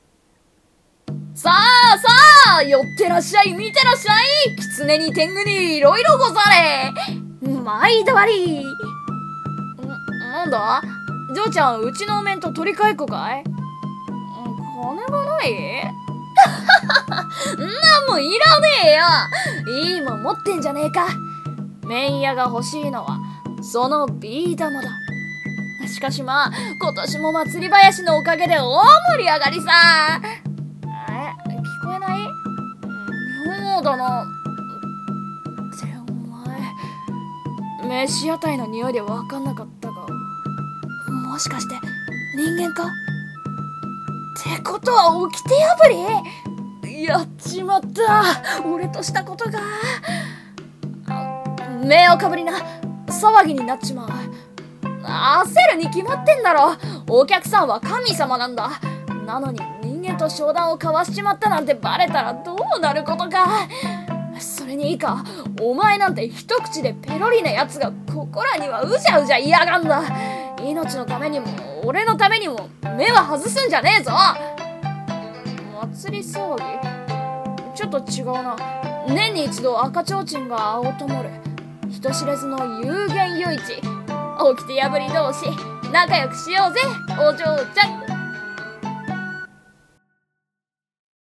【声劇】幽玄夜市のアヤカシ商人